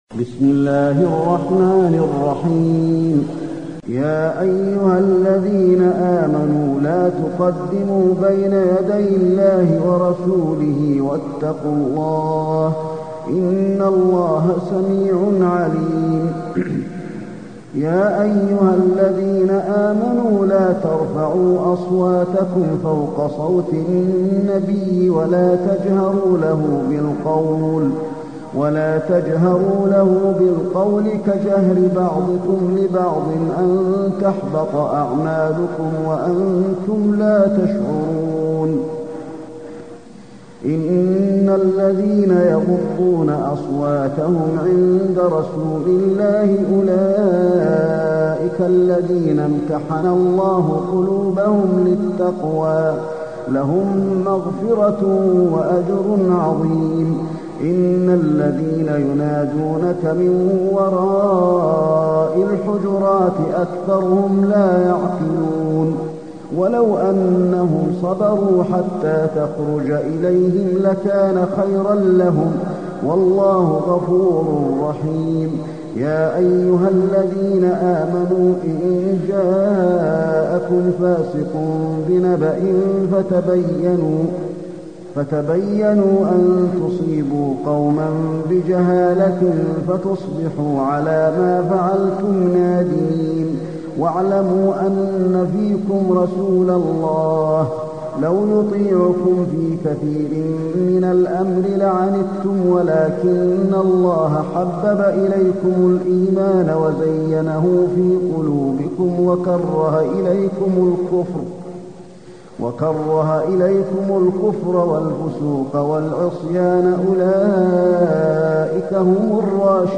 المكان: المسجد النبوي الحجرات